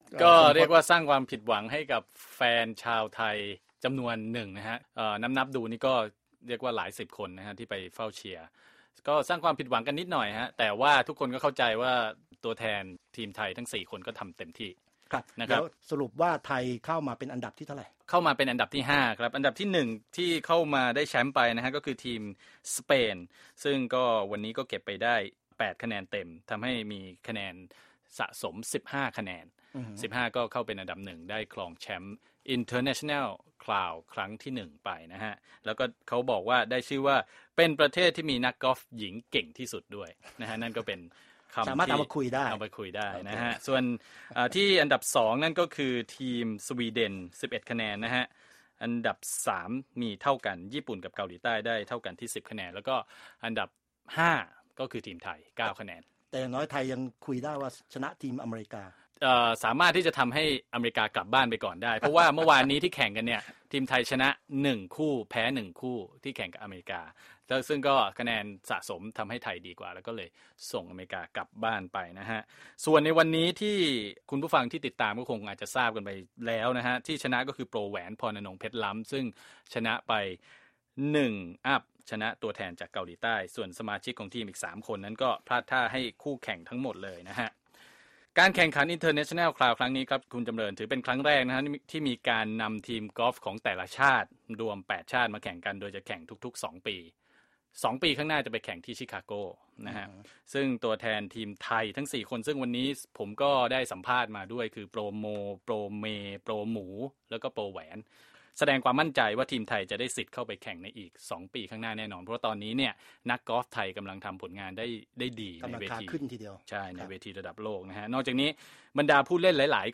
สัมภาษณ์ 4 นักกอล์ฟสาวไทย หลังการแข่งขัน International Crown เสร็จสิ้นลง